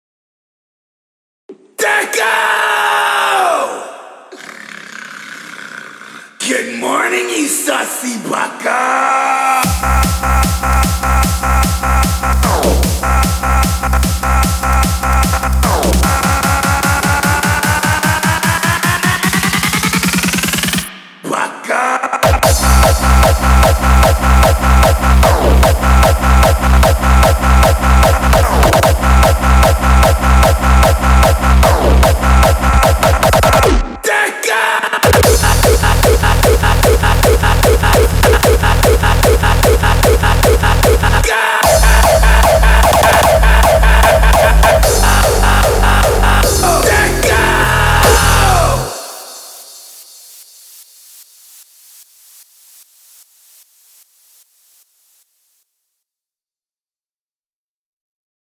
hardstyle version remix